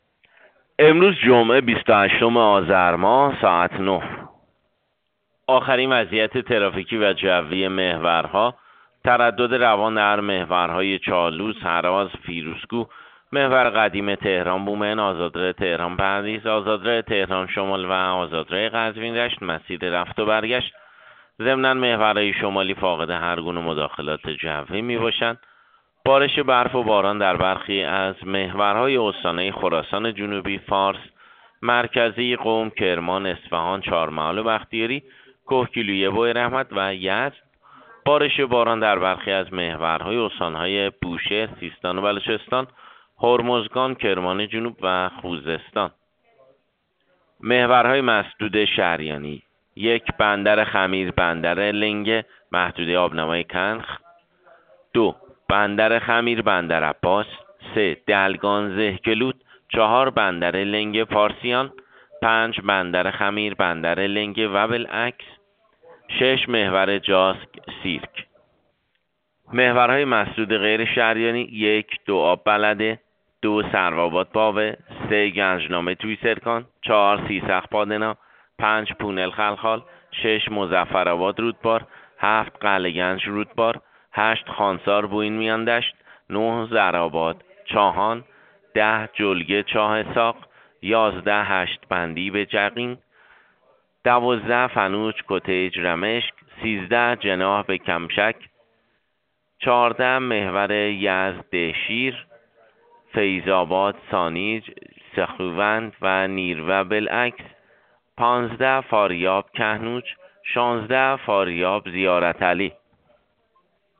گزارش رادیو اینترنتی از آخرین وضعیت ترافیکی جاده‌ها ساعت ۹ بیست‌و هشتم آذر؛